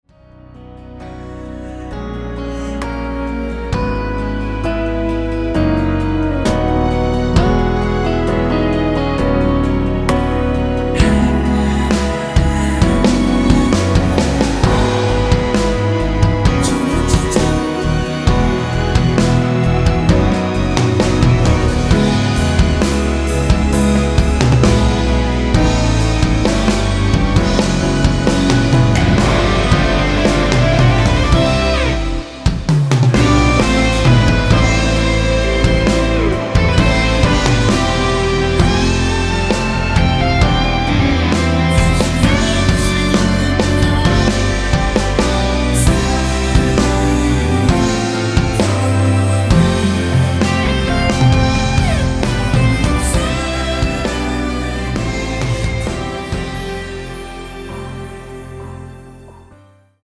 원키에서(-2)내린 (1절앞+후렴)으로 진행되는 코러스 포함된 MR입니다.
◈ 곡명 옆 (-1)은 반음 내림, (+1)은 반음 올림 입니다.
앞부분30초, 뒷부분30초씩 편집해서 올려 드리고 있습니다.
중간에 음이 끈어지고 다시 나오는 이유는